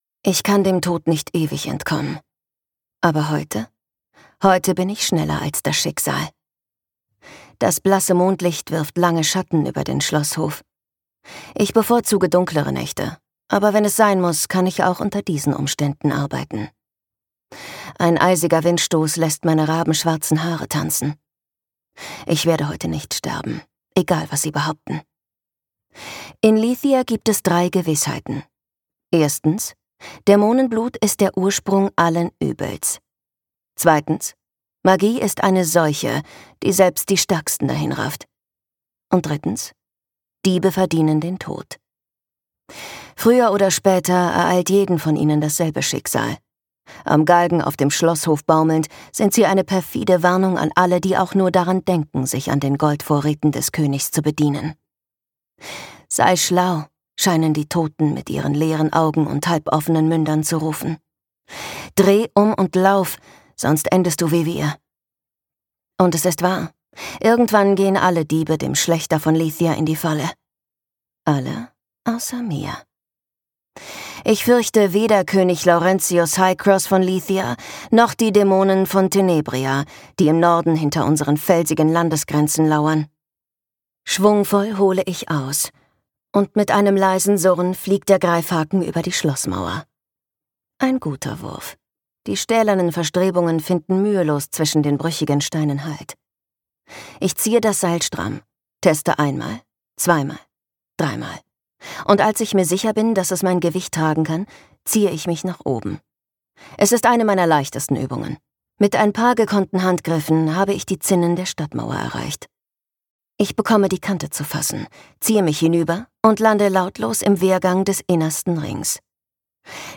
Der Auftakt einer dämonisch guten Romantasy-Dilogie – rasant, unheilvoll und absolut atemlos erzählt!
Gekürzt Autorisierte, d.h. von Autor:innen und / oder Verlagen freigegebene, bearbeitete Fassung.